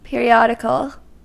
Ääntäminen
US : IPA : [ˌpɪr.i.ˈɑː.dɪ.kəl]